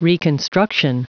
Prononciation du mot reconstruction en anglais (fichier audio)
Prononciation du mot : reconstruction